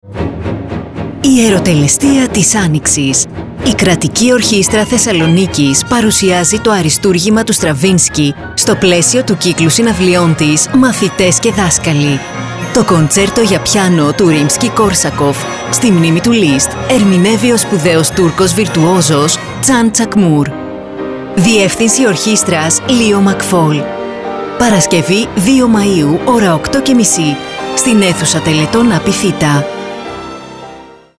Ραδιοφωνικό σποτ 2μαι25_Ιεροτελεστία της Άνοιξης.mp3